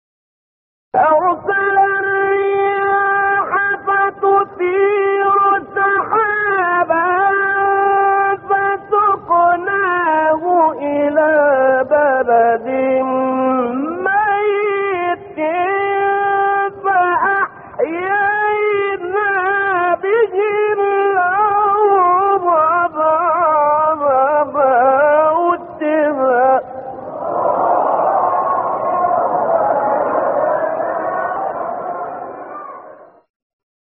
گروه شبکه اجتماعی: نغمات صوتی از قاریان ممتاز مصری ارائه می‌شود.